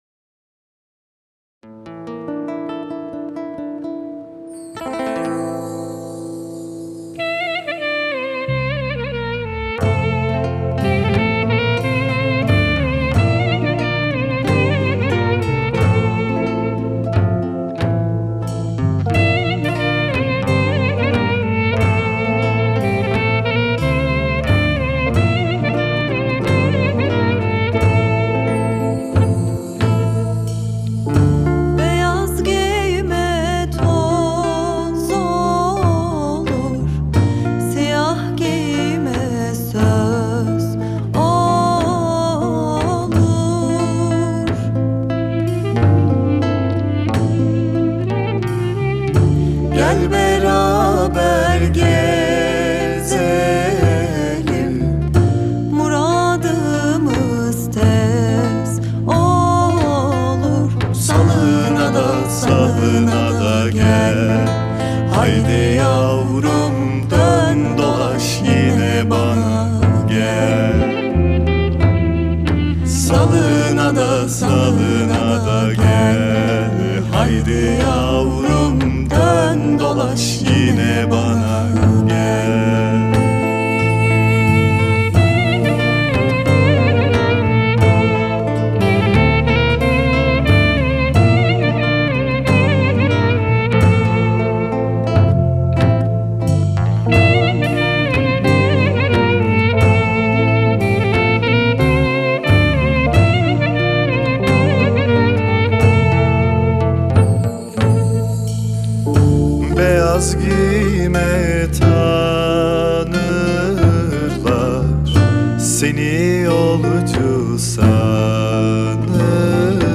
dizi müziği, duygusal hüzünlü rahatlatıcı şarkı.